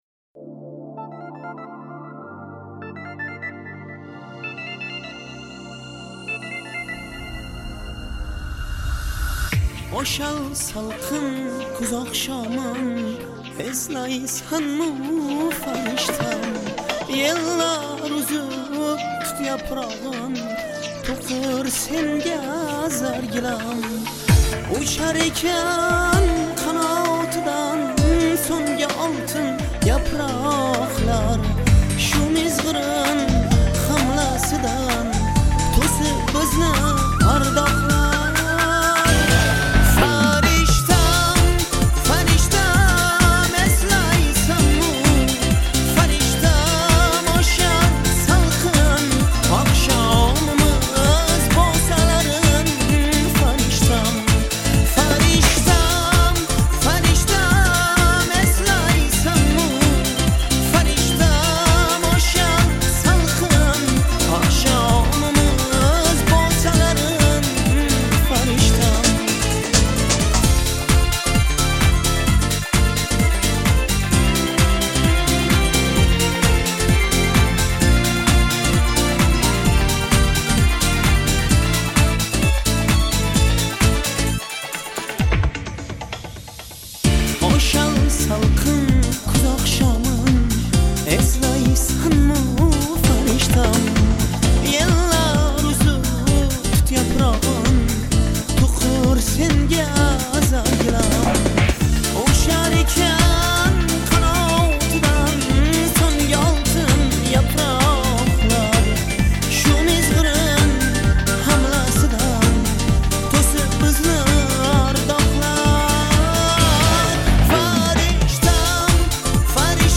• Жанр: Узбекская музыка